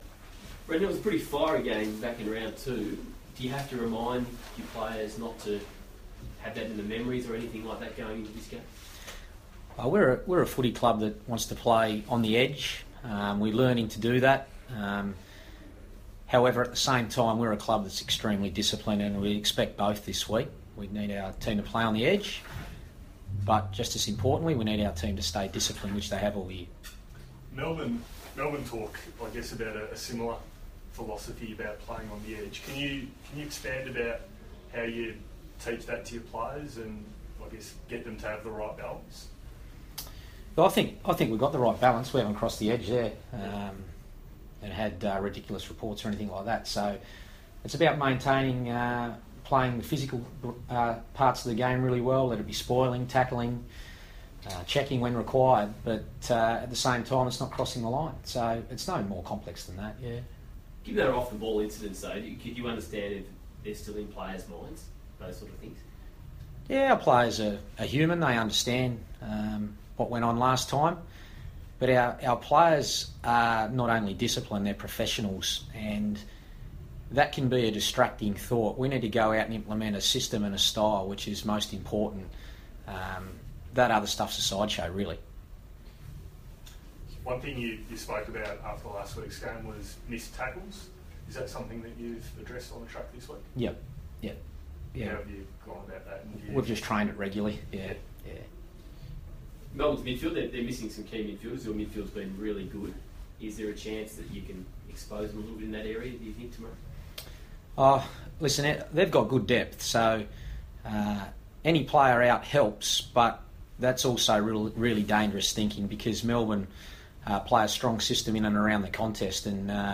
Brendon Bolton press conference - July 8
Carlton coach Brendon Bolton fronts the media ahead of the Blues' Round 16 clash with Melbourne.